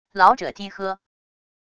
老者低喝wav音频